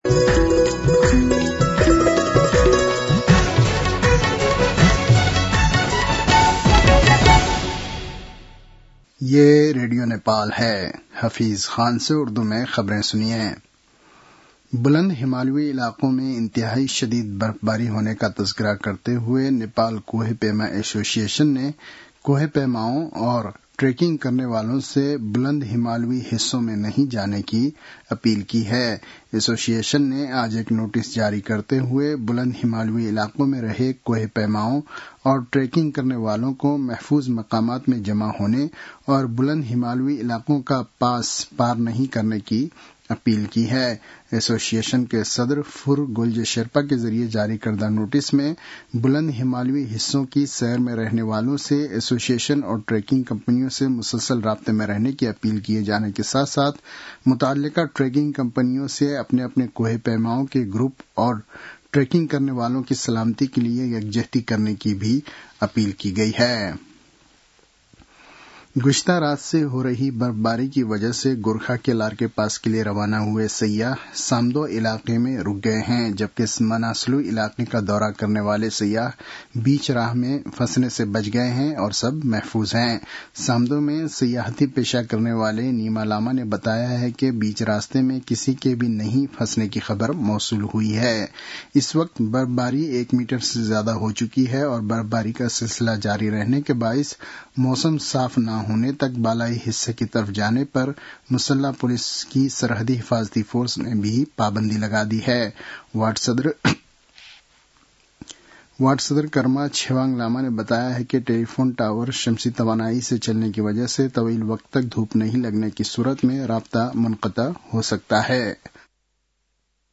उर्दु भाषामा समाचार : ११ कार्तिक , २०८२